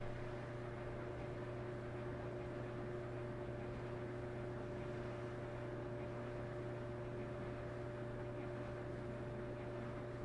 电动修剪机的嗡嗡声
描述：电动修剪器的嗡嗡声。
Tag: 剃须 余音绕梁 巴斯 哼哼 修边机 OWI